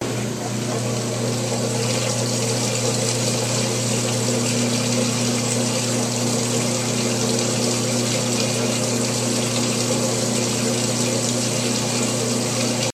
washing3.ogg